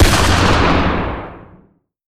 Sniper_Rifle3.ogg